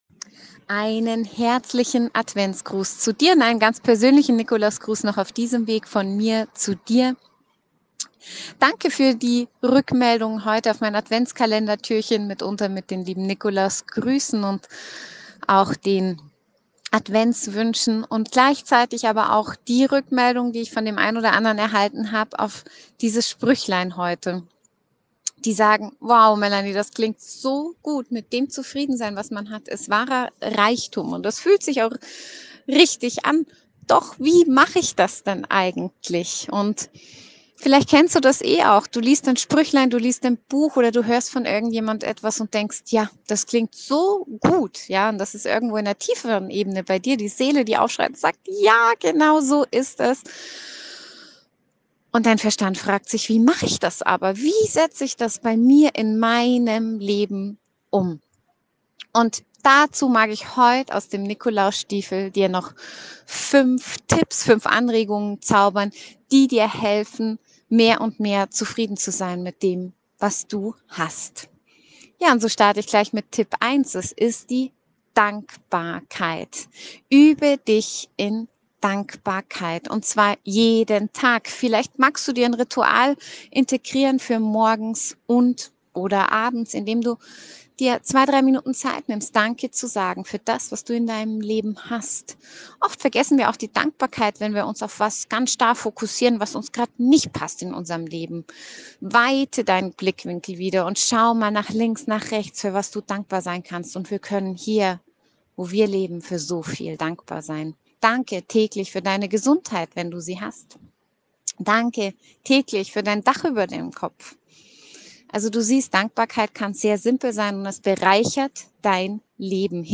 In diesem Jahr hab ich den ein oder anderen Spruch noch mit einer persönlichen Sprachnachricht ergänzt.